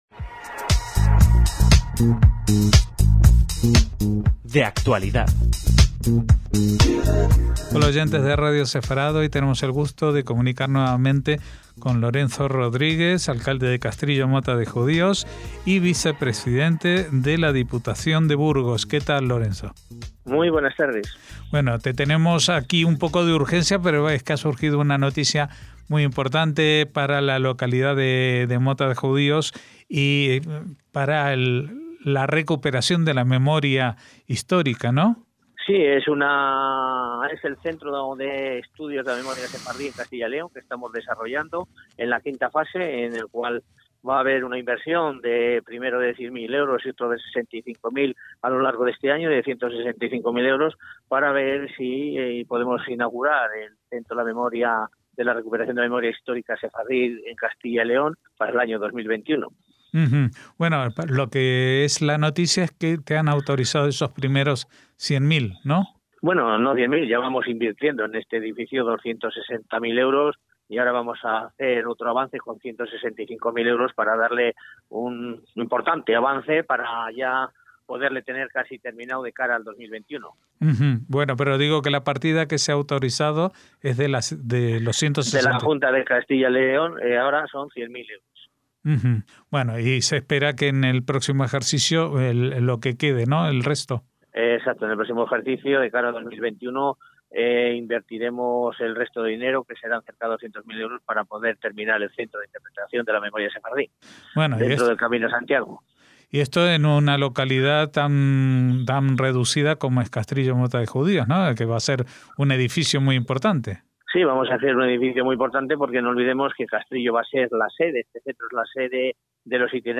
Nos atiende Lorenzo Rodríguez, alcalde de la localidad y Vicepresidente de la Diputación de Burgos.